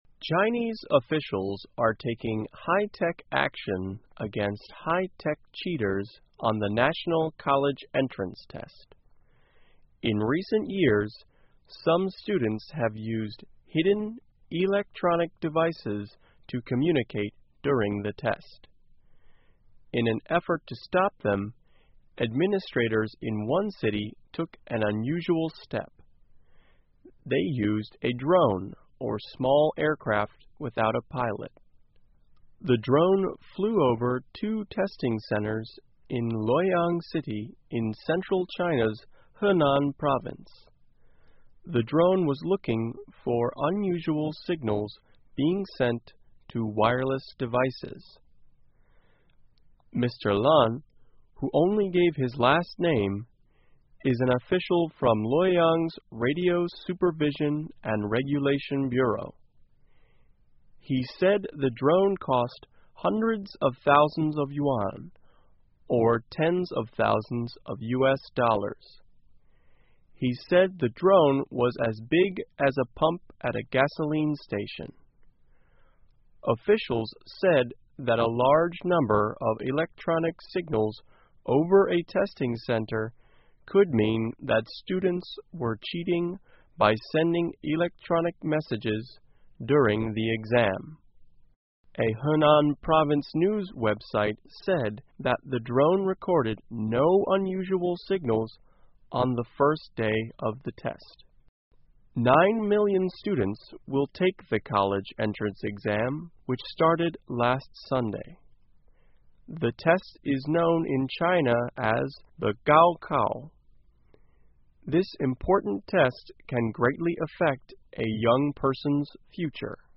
VOA慢速英语2015 中国动用无人机防止考试作弊 听力文件下载—在线英语听力室